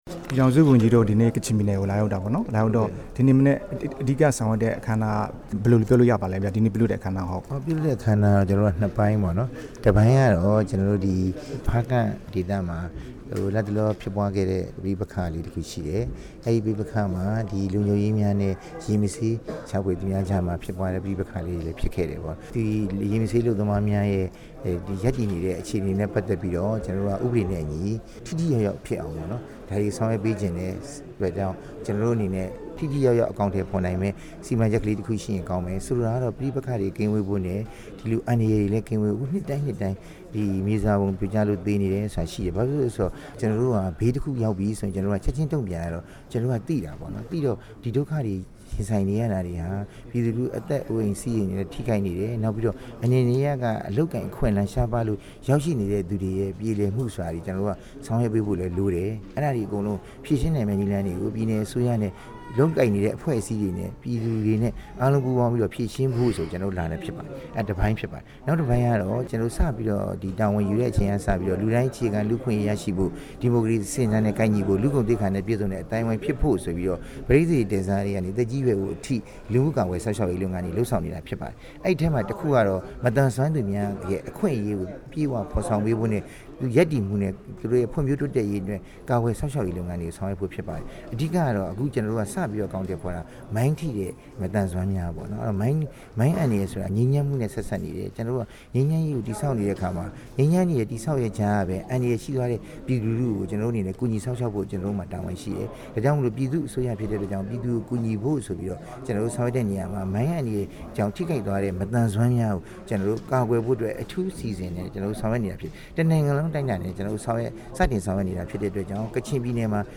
ဖားကန့်အရေး မေးမြန်းချက်